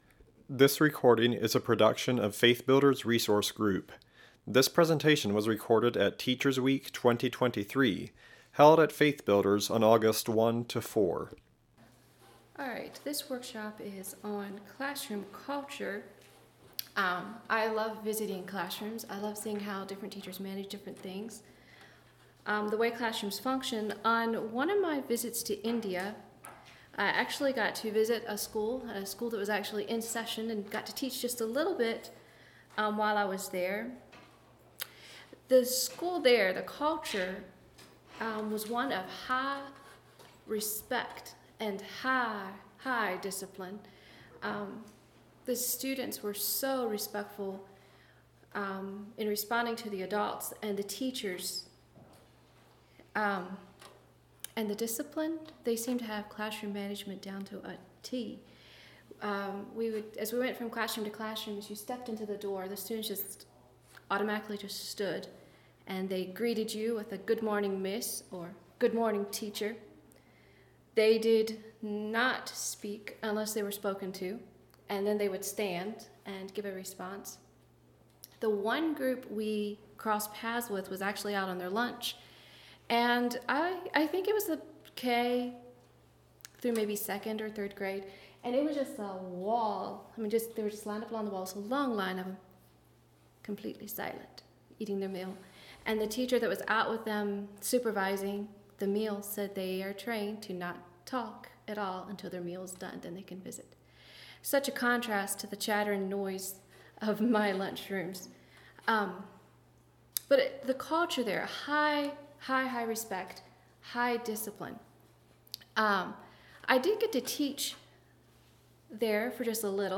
Home » Lectures » Cultivating Classroom Culture